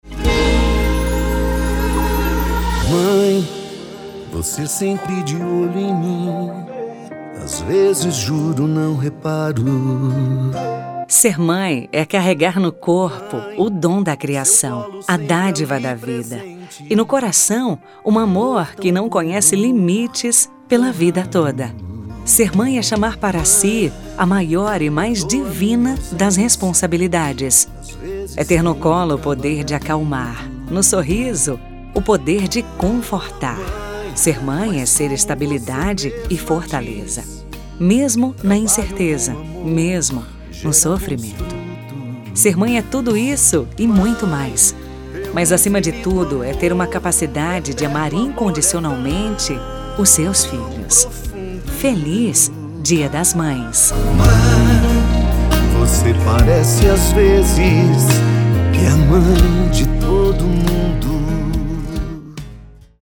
Voz Padrão: